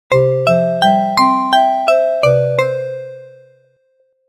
SE（アイテムゲット）
アイテムゲッ、ステージクリアしたときなどのSEです。
SE バトル 演出 SE ファンファーレ スポンサーリンク シェアする Twitter Facebook LINE コピー Pure Score Pure Score 関連記事 バトル SE（勝利） 勝利 ダウンロード 利用規約をご確認の上、ご使用ください。